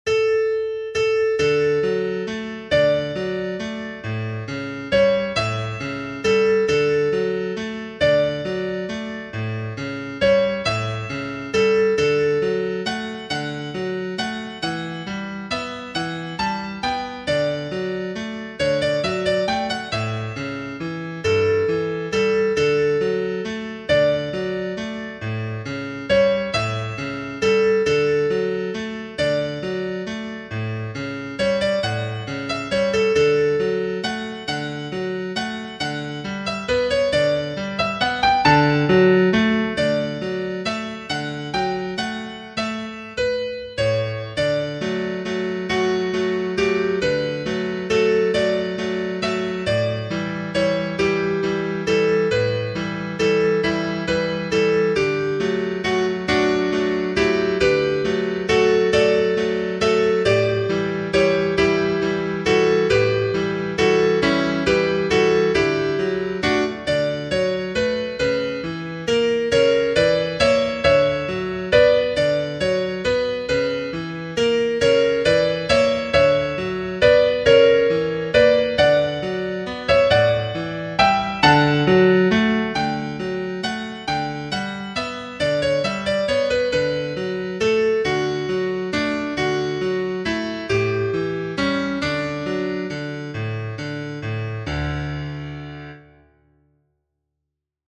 Opera